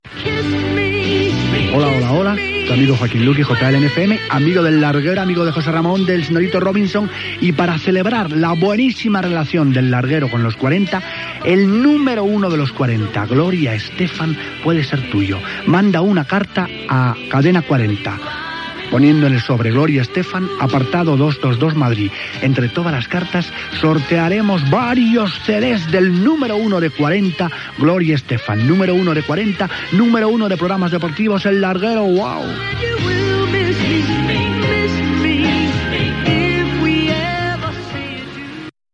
Anunci del sorteig d'exemplars del disc de Gloria Estefan número 1 del programa Los 40 Principales.